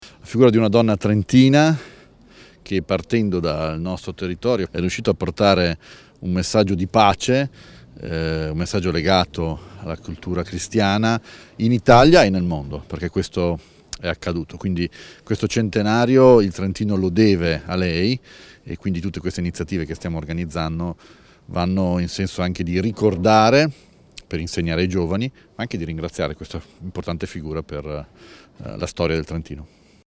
int_Fugatti_07_12.mp3